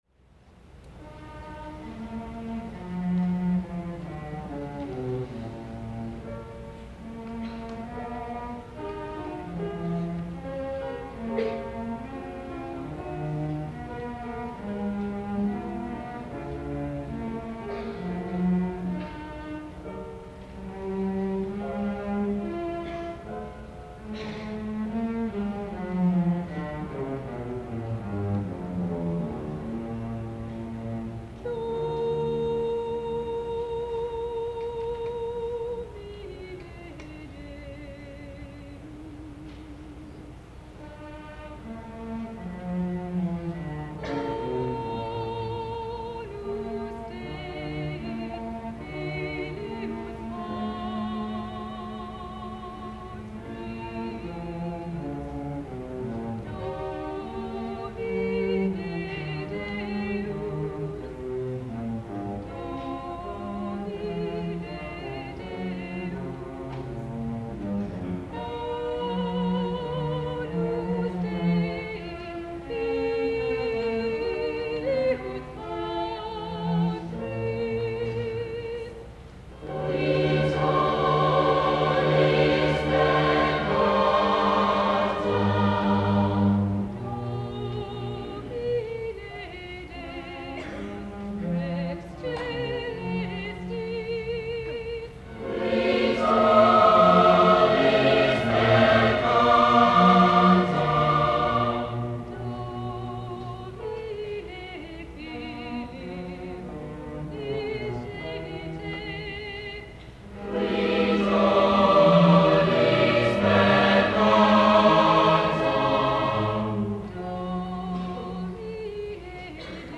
Christmas Concert 1972
Clay High Gym